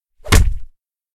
非循环音(SE)
0005_给他一拳！.ogg